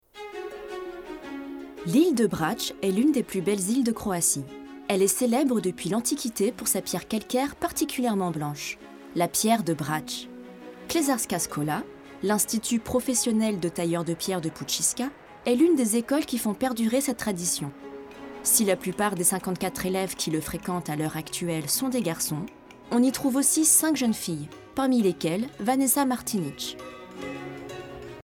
Bandes-son
Voix off
16 - 32 ans - Mezzo-soprano